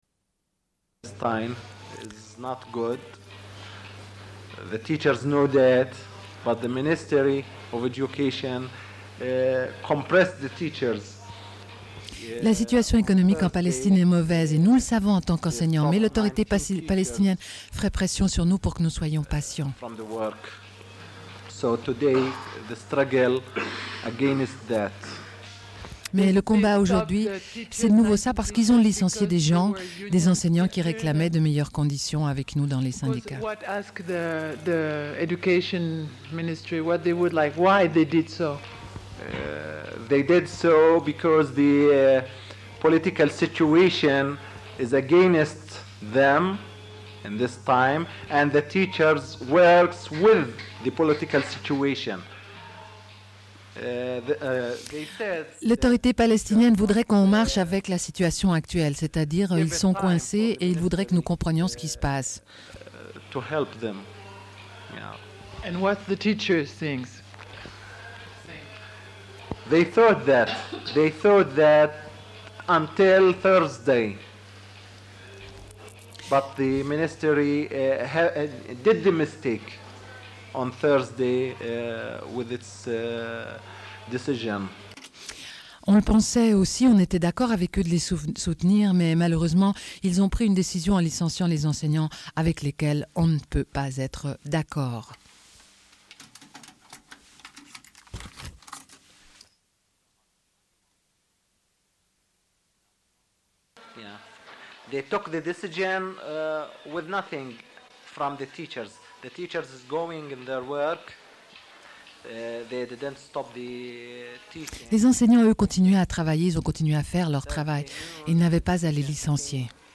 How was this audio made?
Diffusion d'un entretien réalisé à l'école Bait Fejar en Palestine, avec des enseignant·es en grève au moment de l'entretien (avril 1997).